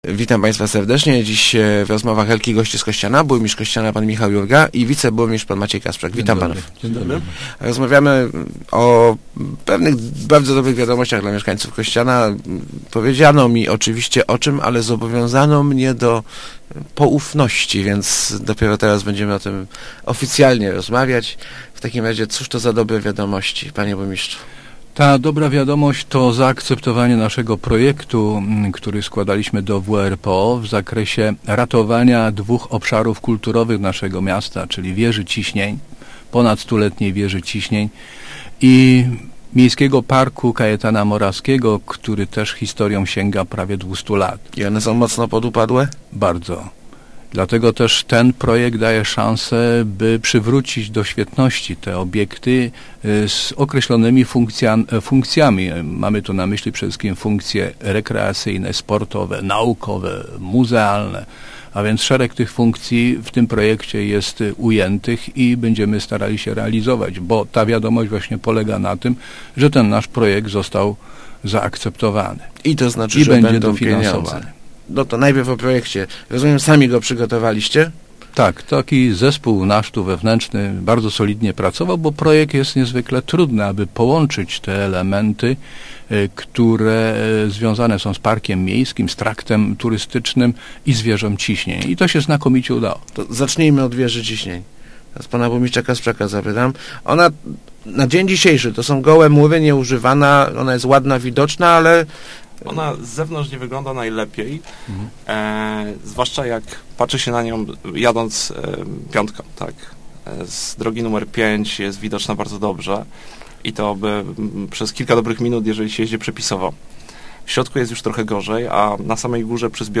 Powstanie tam wewnętrzna ścianka wspinaczkowa, ekspozycja muzealna i obserwatorium astronomiczne – mówili w Rozmowach Elki burmistrz Kościana Michał Jurga i jego zastępca Maciej Kasprzak. Samorząd miejski otrzyma na realizację dofinansowanie ze środków europejskich.